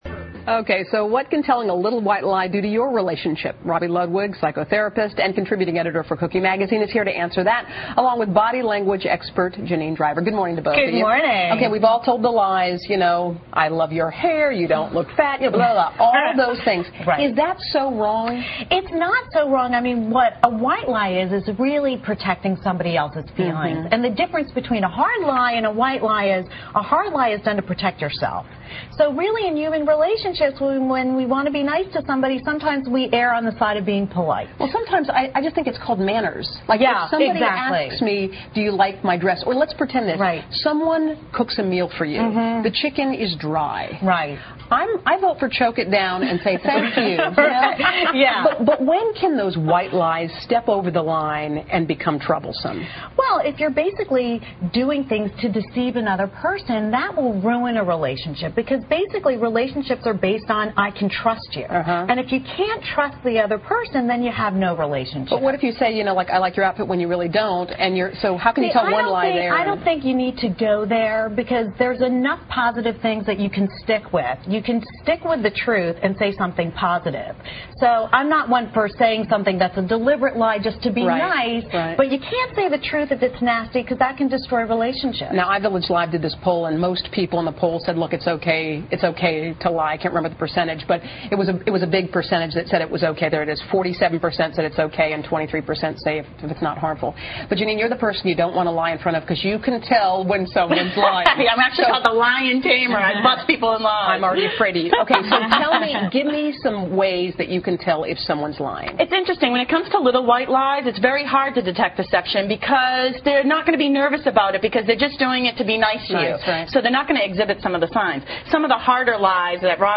访谈录[Interview]2007-10-28:该不该说善意的谎言 听力文件下载—在线英语听力室